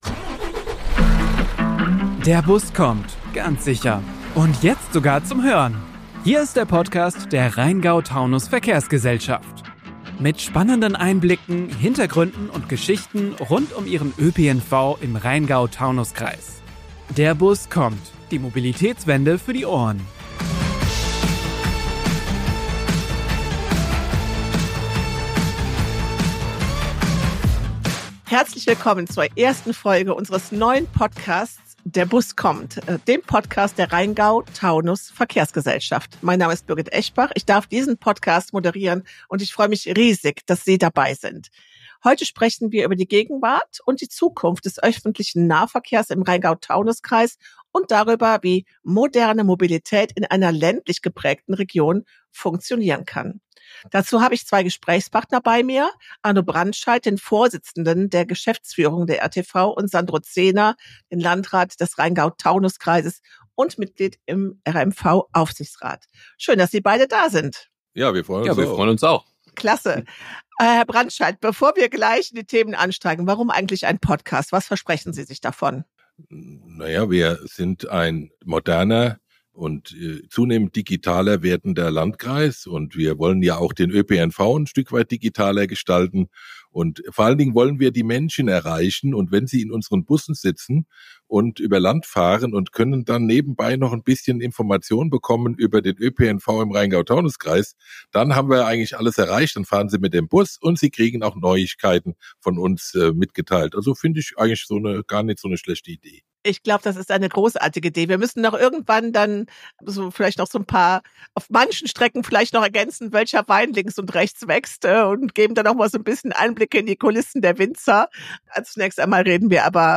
Gast: Sandro Zehner, Landrat und Verkehrsdezernent des Rhein-Taunus-Kreises